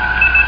beep6.mp3